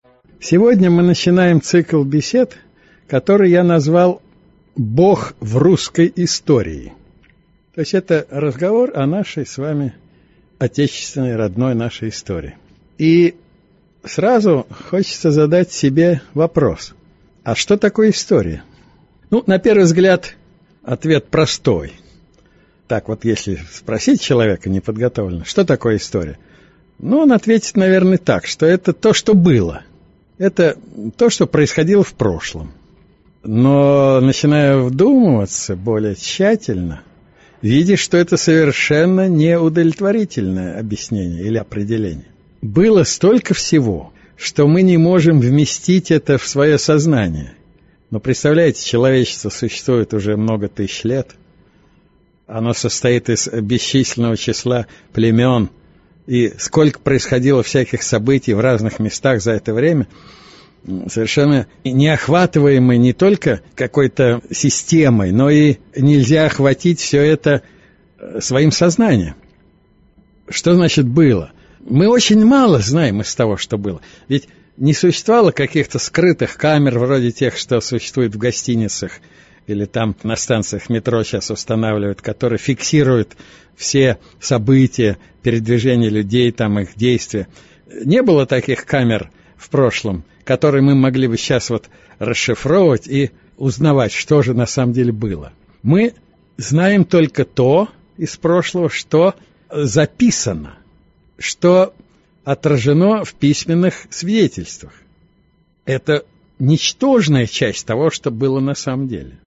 Aудиокнига Бог в Русской истории